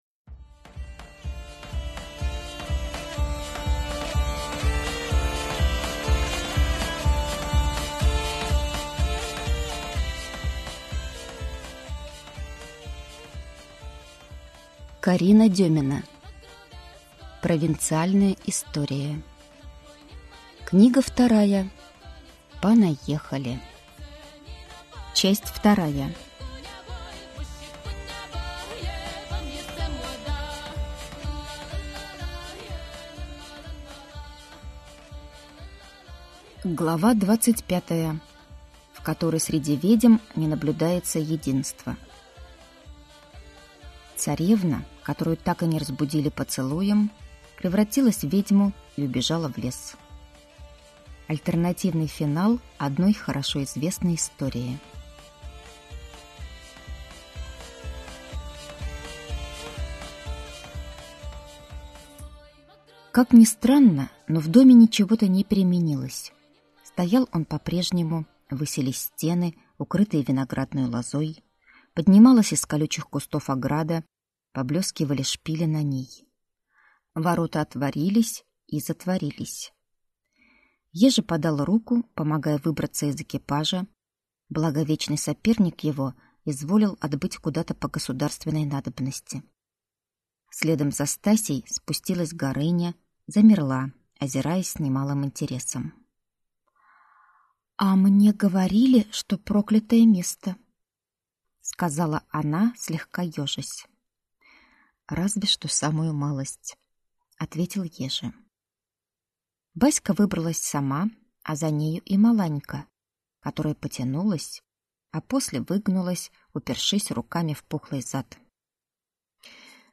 Aудиокнига Провинциальная история (книга 2 часть 2)